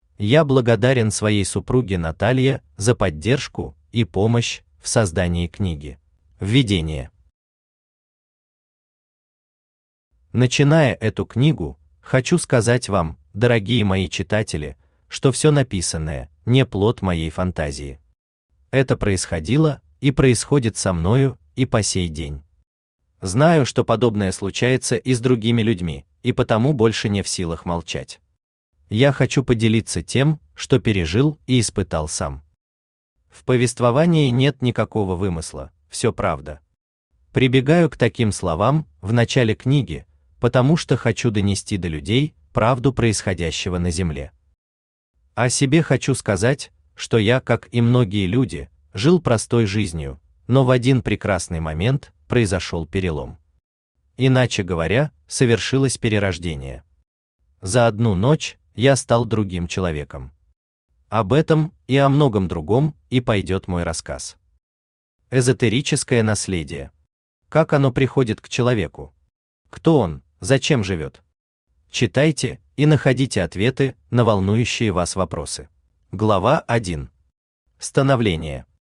Аудиокнига Жизнь до и после. Серия «Я есмь». Часть I | Библиотека аудиокниг
Часть I Автор Владимир Федорович Ломаев Читает аудиокнигу Авточтец ЛитРес.